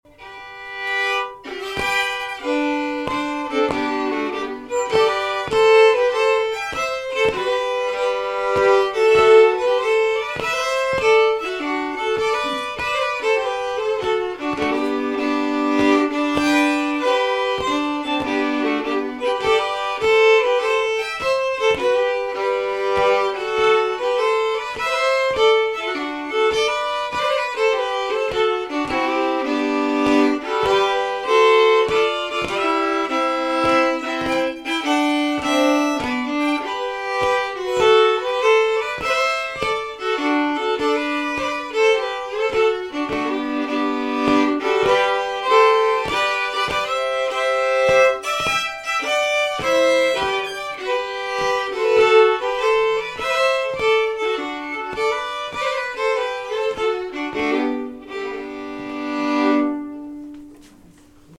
(harmony)